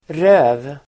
Ladda ner uttalet
röv substantiv (kan uppfattas som stötande), arse [may be offensive]Uttal: [rö:v] Böjningar: röven, rövarDefinition: bakdel (backside)